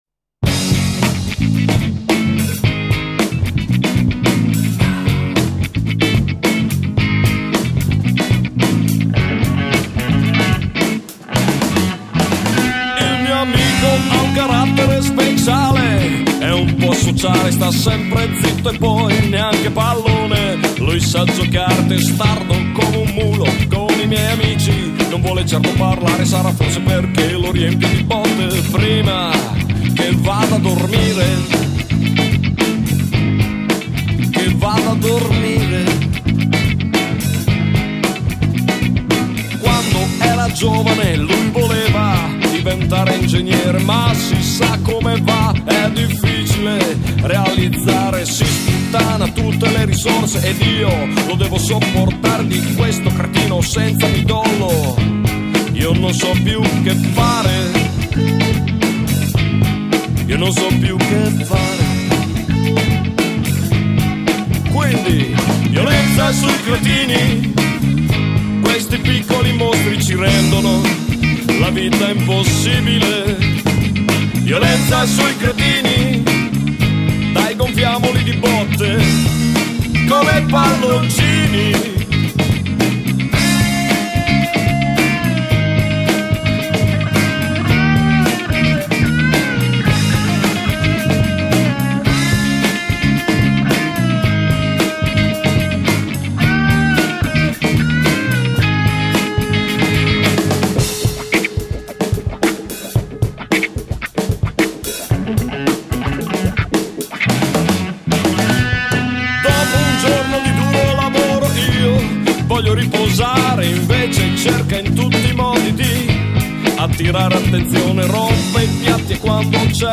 Musica Demenziale e altro...